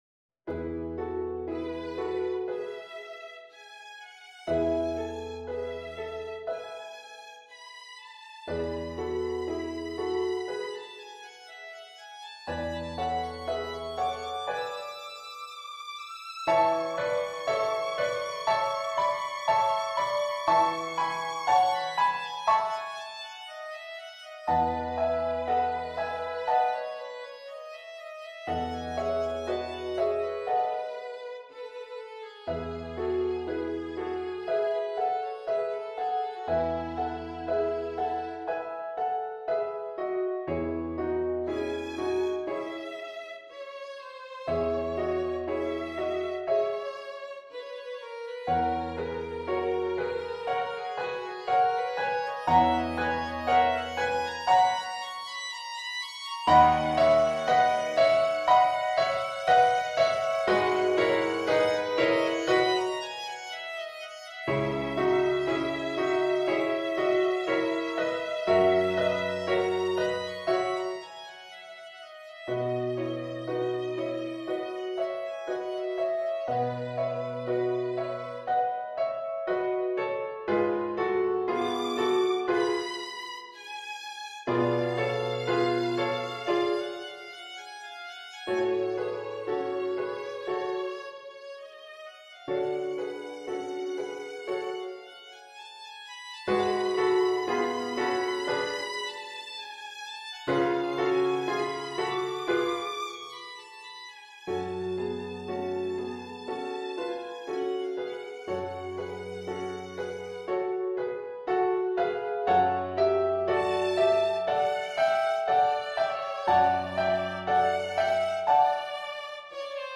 Instrument: Violin
Style: Classical